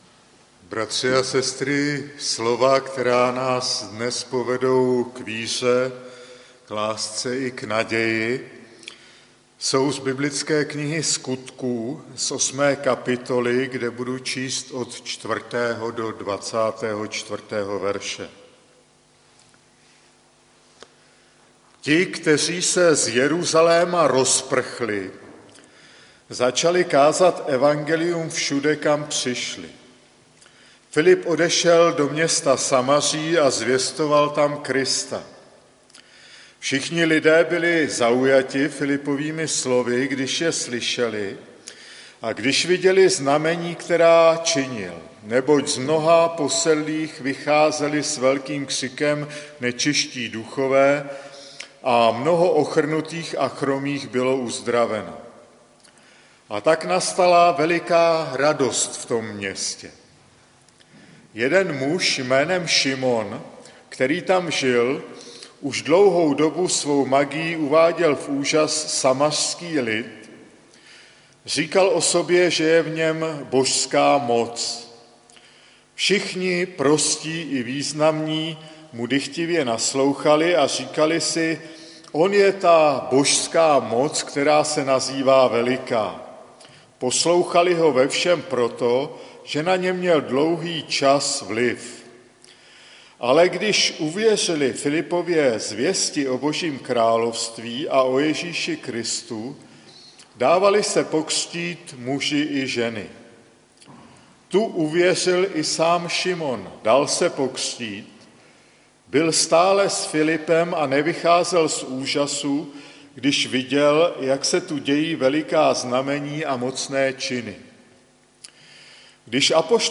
Kázání 6.11.2016 | Farní sbor ČCE Nové Město na Moravě